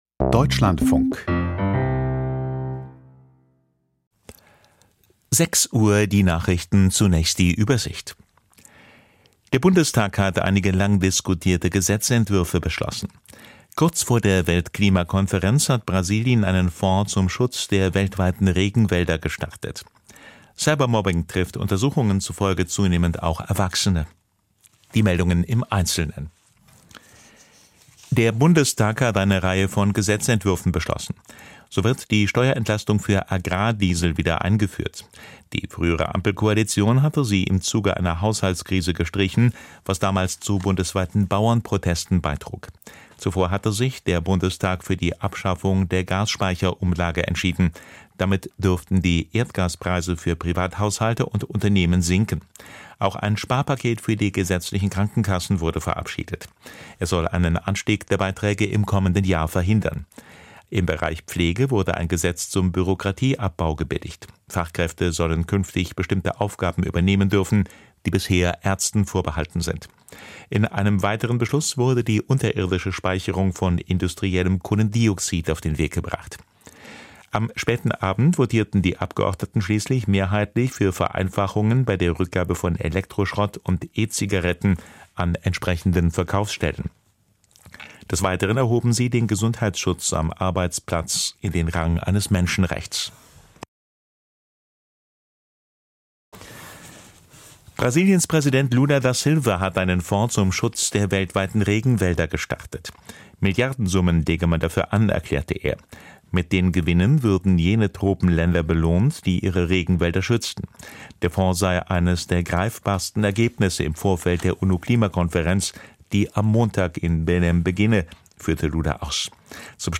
Die Nachrichten vom 07.11.2025, 06:00 Uhr